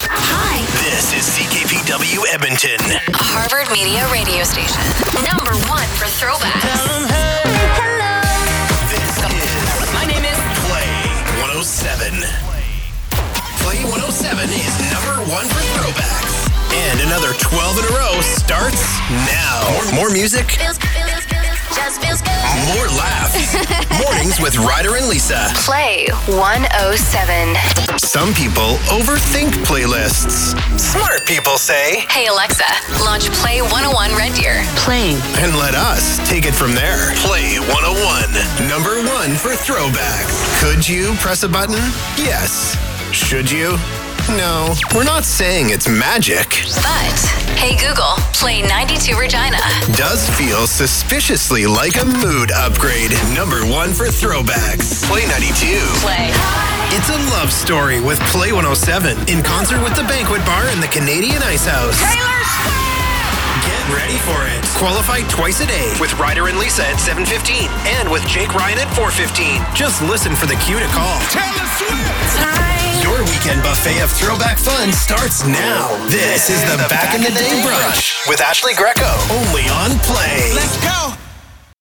Male
My voice has "today's sound". It's young, fresh, cool, natural, conversational, relatable. I can do anything from laid back to hard sell and excited. I have a slight raspy and deep voice but can deliver upbeat young sounding copy with ease. I can do a cool, hip radio imaging voice from excited to smooth "mtv" type deliveries.
Radio / TV Imaging
Radio Imaging Pop/Chr/Hotac